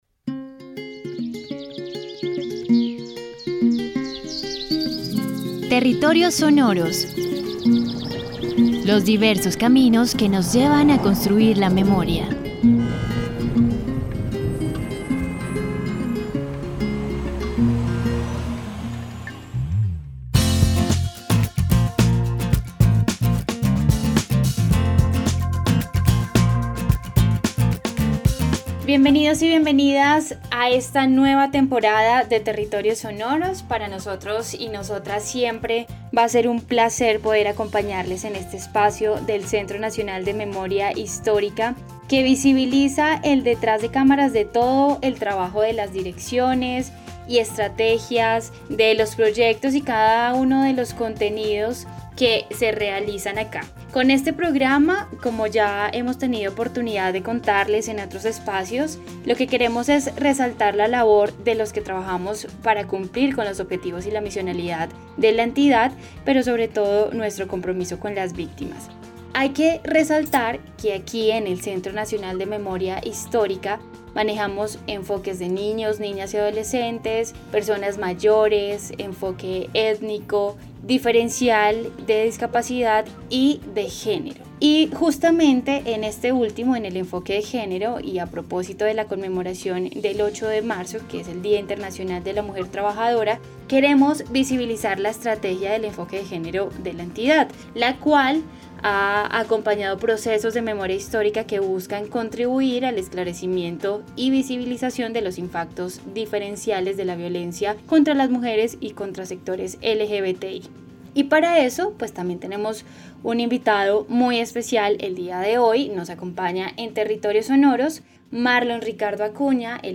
Conversación de como se realiza el enfoque de género en el CNMH.